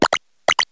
sovereignx/sound/direct_sound_samples/cries/tandemaus.aif at a502427a7f5cc8371a87a7db6bb6633e2ca69ecb